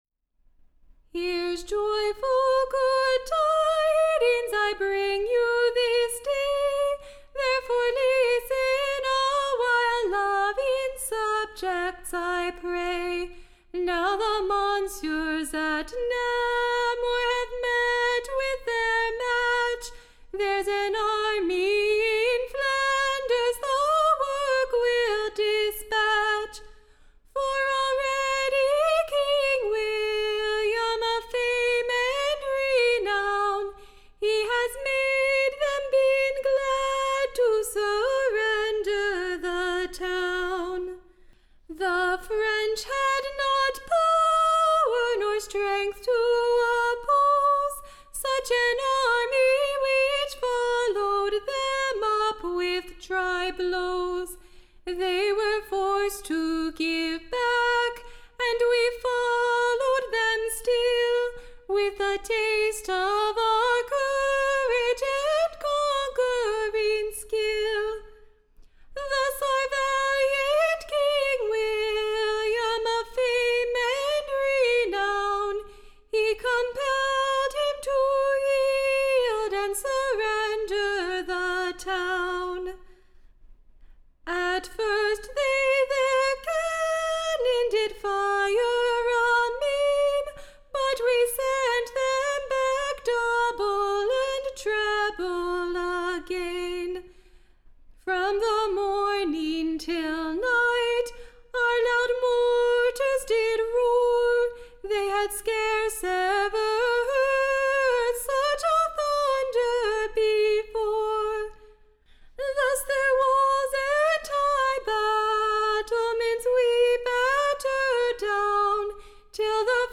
Recording Information Ballad Title The Glory of Flanders: / OR, / The Triumphant Army's Victory over the French at Na- / mur, forcing them to yield and surrender up the Town to King / WILLIAM, the Royal Conqueror.